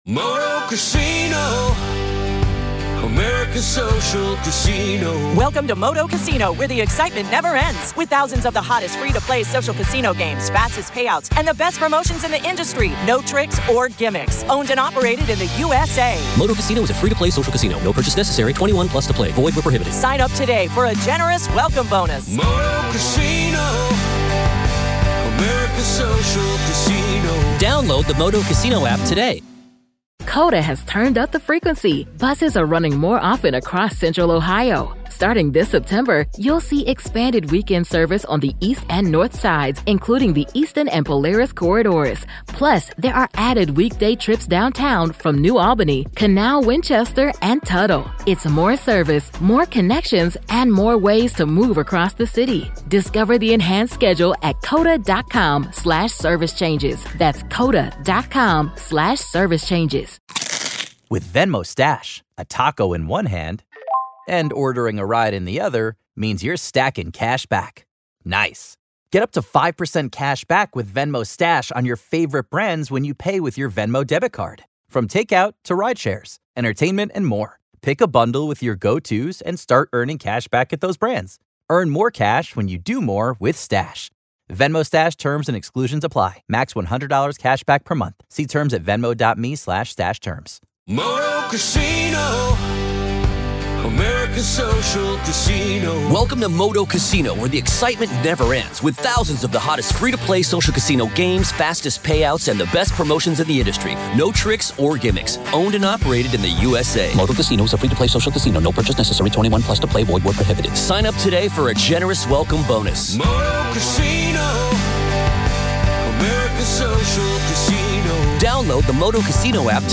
In this gripping conversation